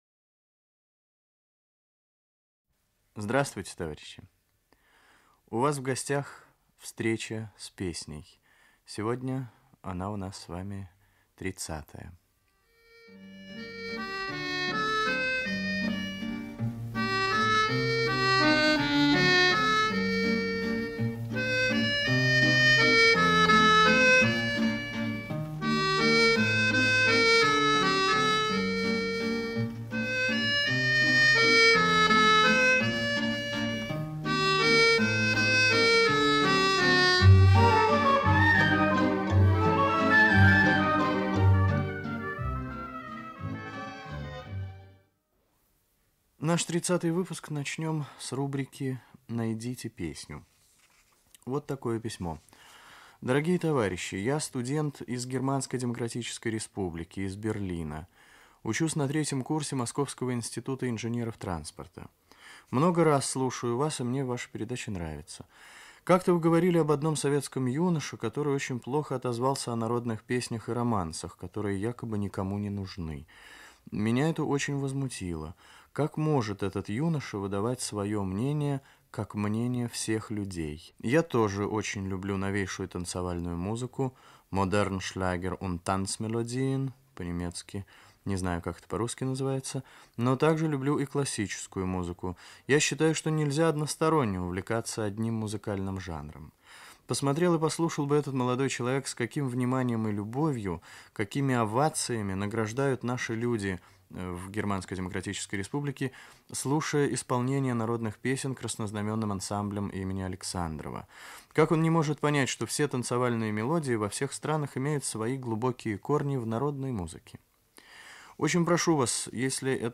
1. Музыкальная заставка к передаче.